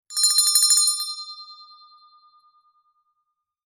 Fast Short Hand Bell Ring Sound Effect
Description: Fast short hand bell ring sound effect. Experience a short hand bell ring with bright, crisp tones perfect for notifications.
Fast-short-hand-bell-ring-sound-effect.mp3